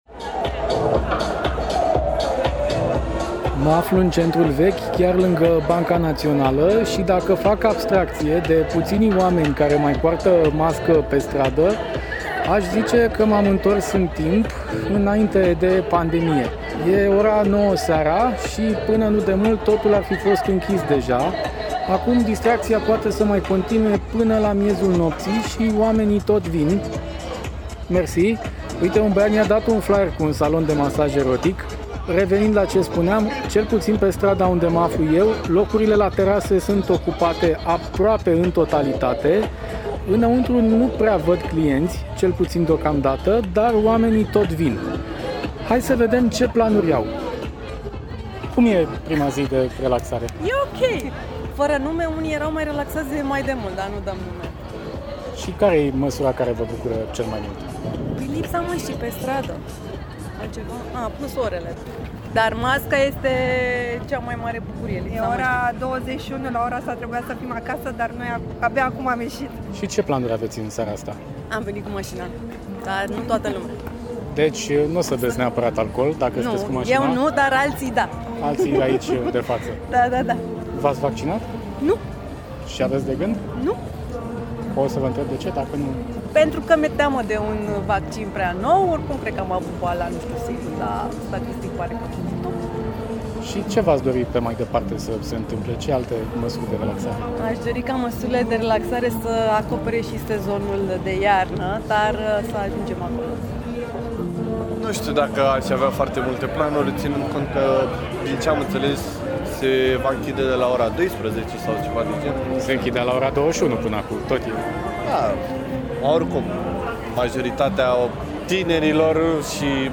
Lumea Europa FM: Prima zi de relaxare în Centrul Vechi | Reportaj
Muzica răsună iar pe străzi, terasele sunt aproape pline și în principiu, se și respectă puținele reguli sanitare care au mai rămas în vigoare.